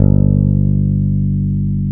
ElectricBass(2)_G1_22k.wav